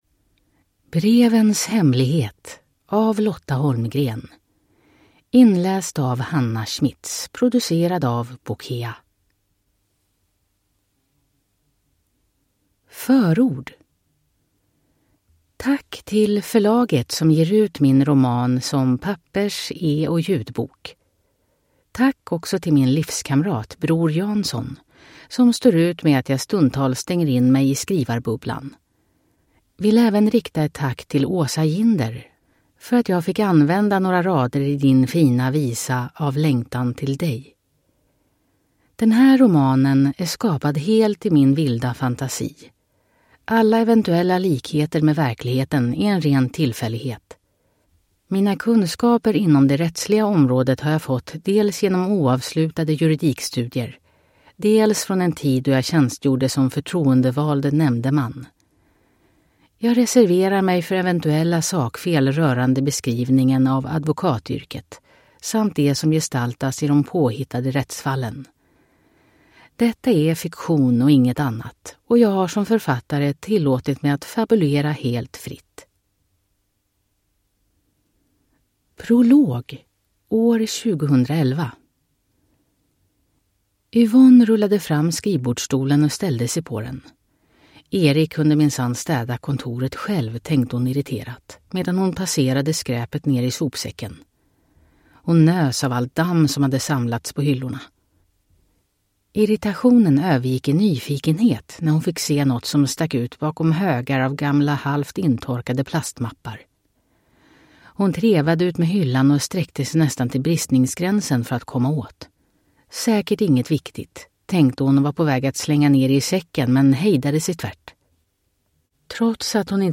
Brevens hemlighet – Ljudbok